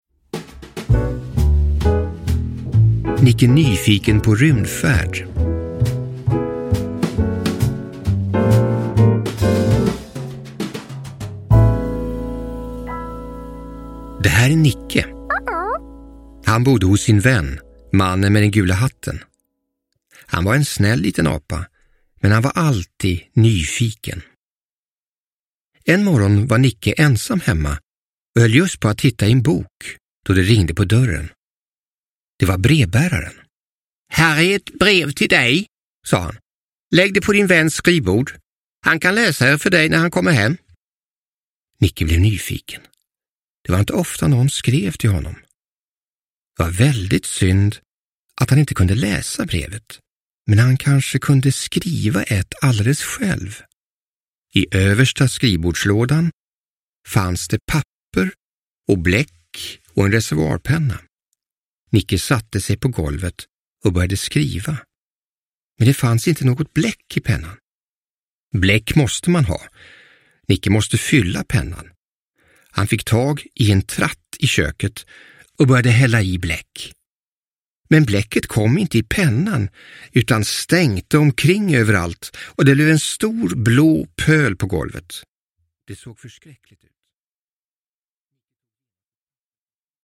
Nicke Nyfiken på rymdfärd – Ljudbok – Laddas ner